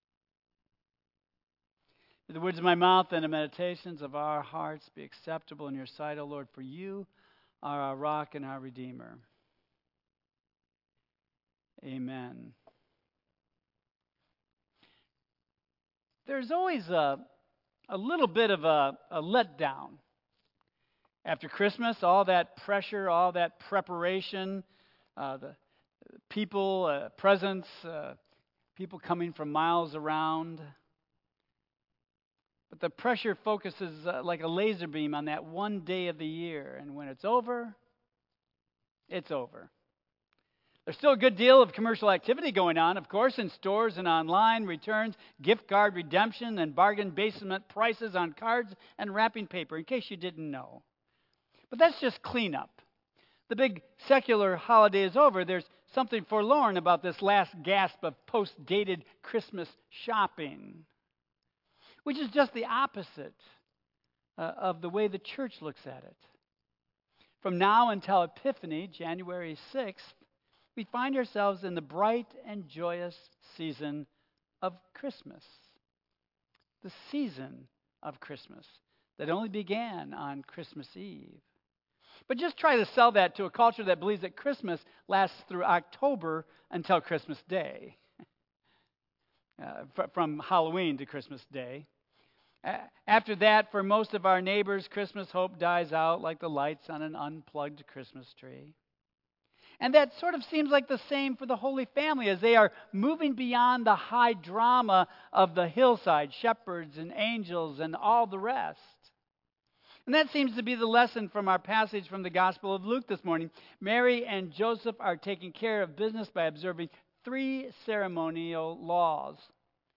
Tagged with Central United Methodist Church , Michigan , Sermon , Waterford , Worship